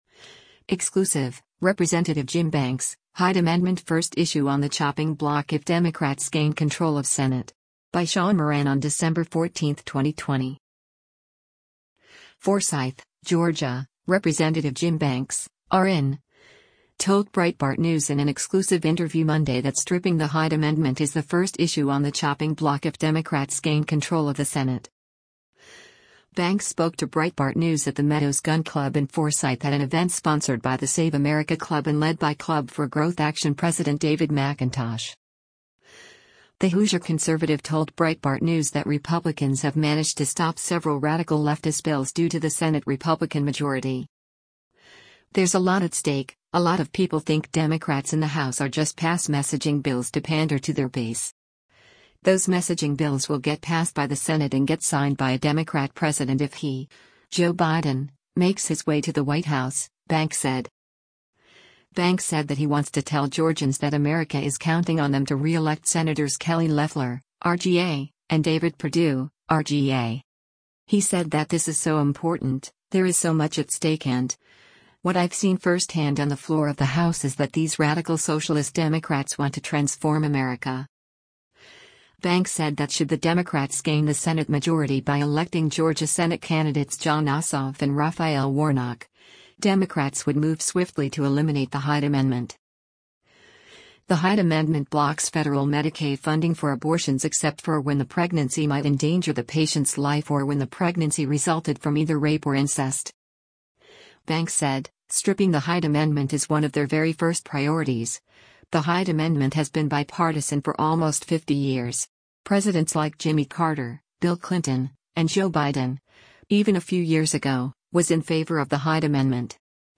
FORSYTH, Georgia — Rep. Jim Banks (R-IN) told Breitbart News in an exclusive interview Monday that stripping the Hyde Amendment is the “first issue on the chopping block” if Democrats gain control of the Senate.
Banks spoke to Breitbart News at the Meadows Gun Club in Forsyth at an event sponsored by the Save America Club and led by Club for Growth Action President David McIntosh.